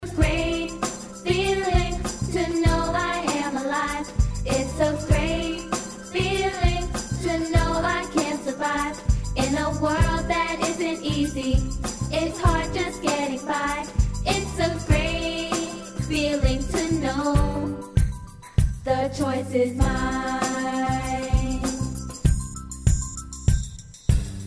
Vocal MP3 song track